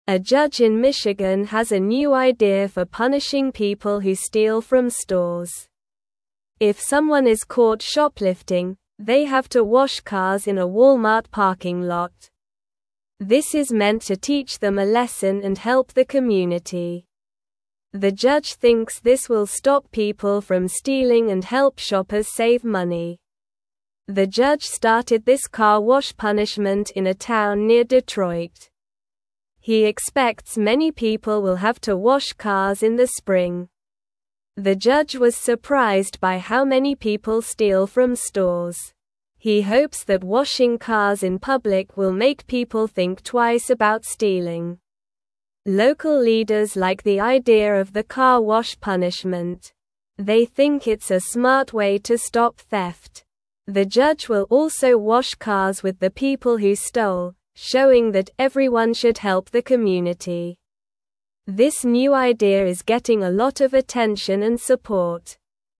Slow
English-Newsroom-Lower-Intermediate-SLOW-Reading-Judges-New-Plan-to-Stop-Store-Stealing.mp3